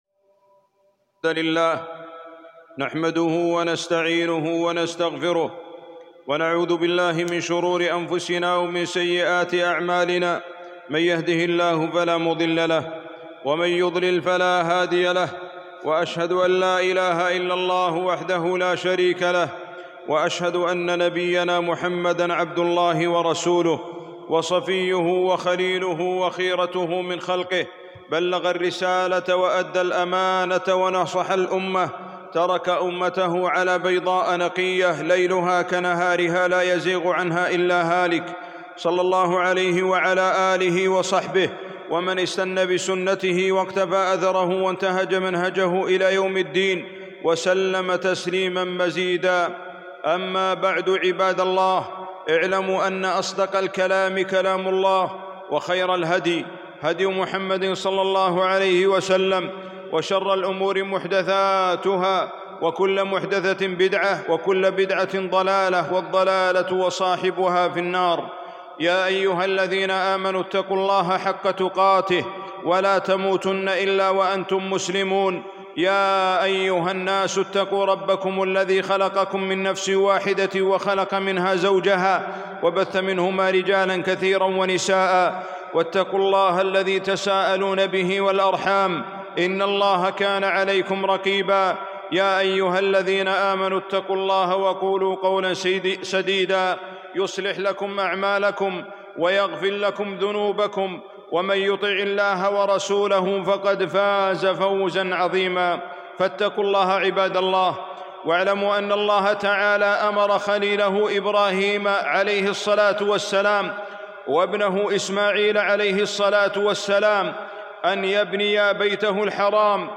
خطبة - قرار الحج في ظل الجائحة 5 ذي القعدة 1441 هــ